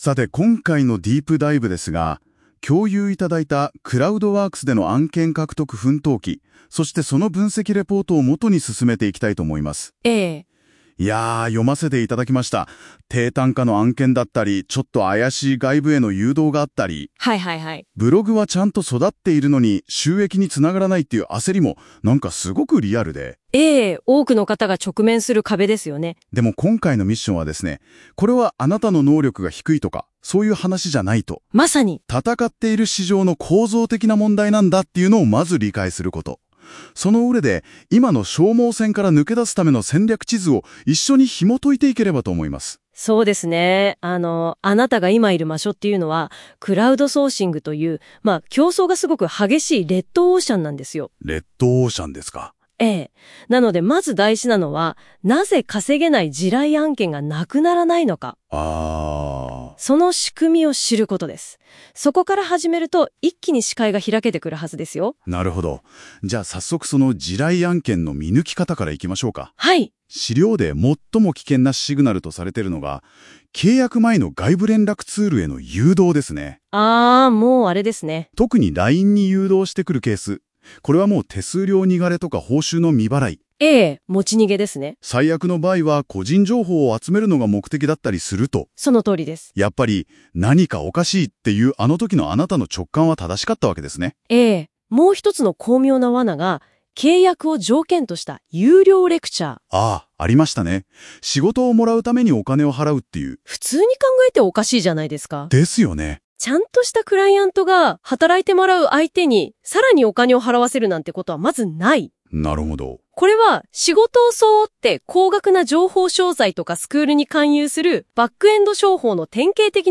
【音声解説】クラウドソーシングの地雷案件と消耗戦脱出戦略